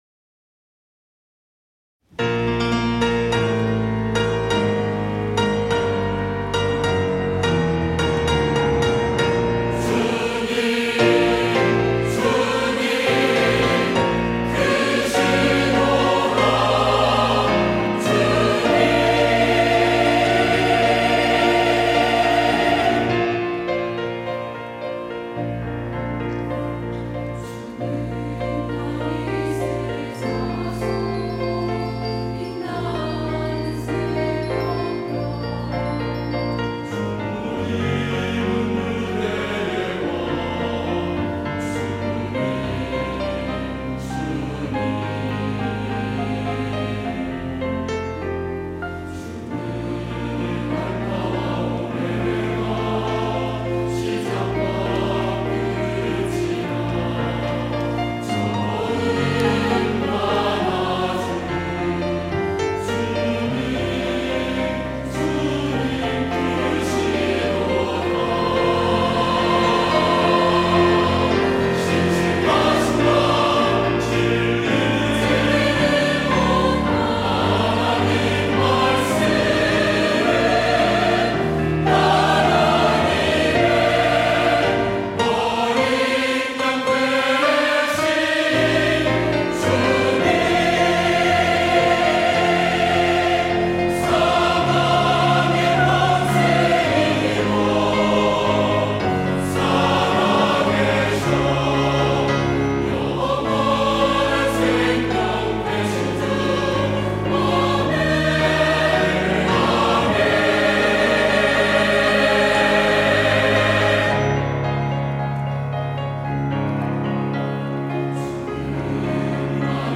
할렐루야(주일2부) - 주님
찬양대